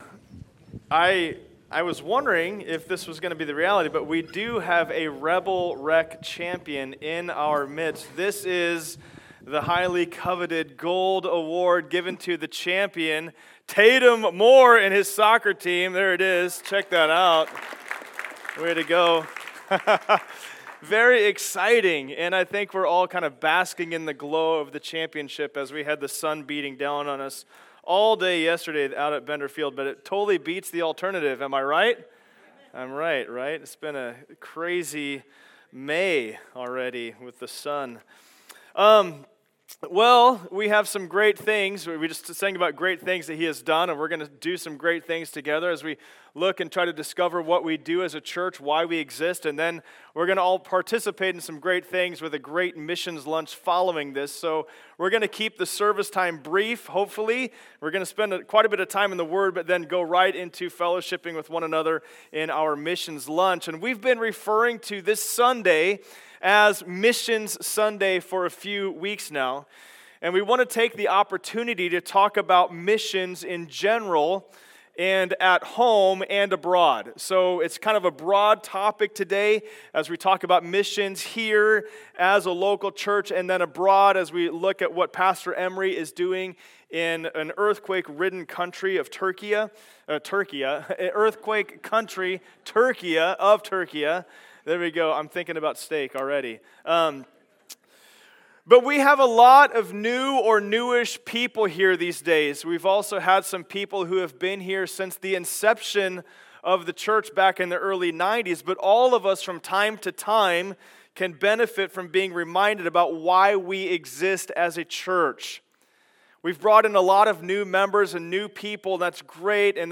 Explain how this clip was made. From Knowing to Doing Service Type: Sunday Service Download Files Notes « The Pathway to Life is…